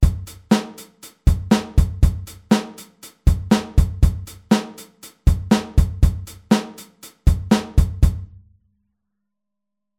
Als vorletzte Variante verschieben wir den rechten Fuß von dem UND nach der Zählzeit 2 hinter die Zählzeit 4.
8telBeats08.mp3